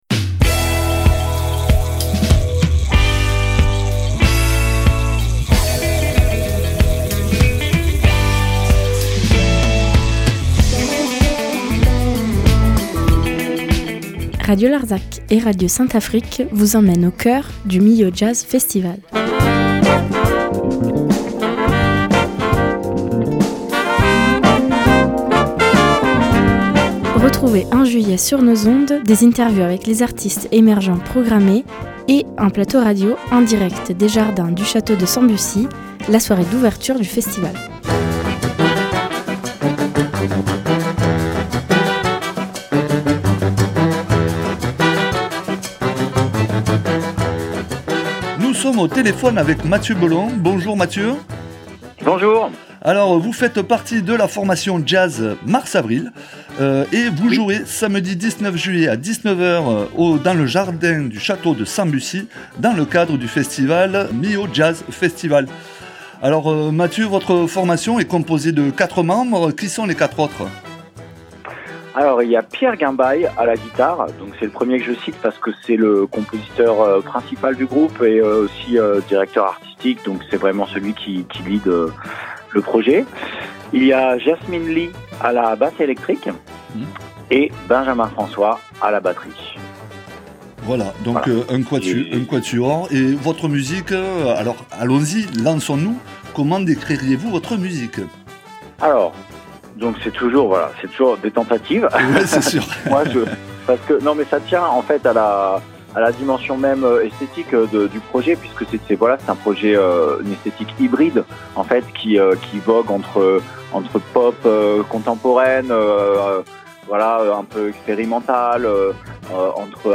Millau Jazz Festival 2025 - Interview